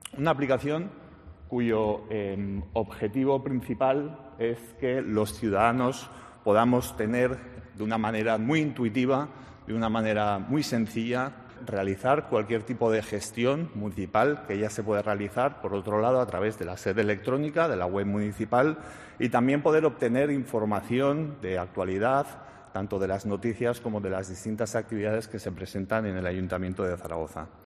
El concejal de Participación, Javier Rodrigo, explica cómo funciona la nueva app 'Conecta Zaragoza'.